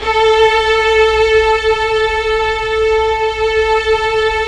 Index of /90_sSampleCDs/Roland L-CD702/VOL-1/STR_Symphonic/STR_Symph. Slow